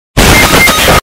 Fortnite Knocked Down Earrape Sound Effect Free Download
Fortnite Knocked Down Earrape